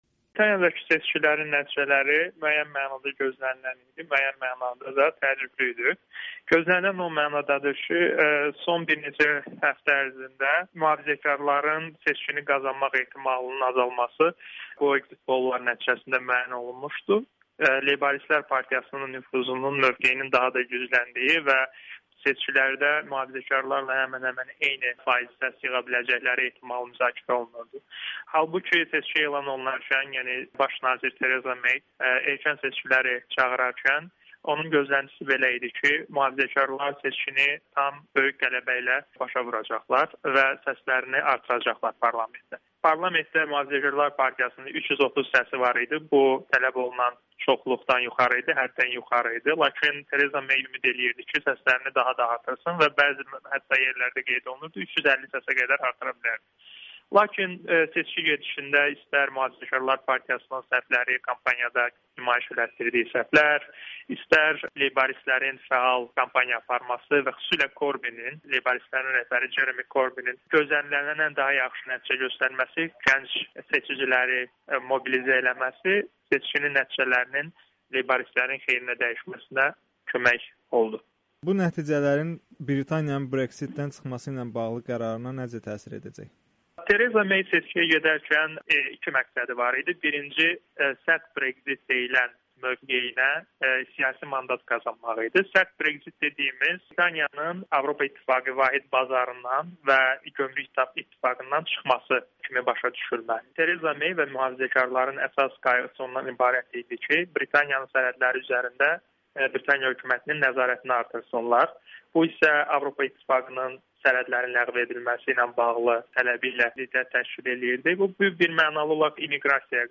Müsahibələr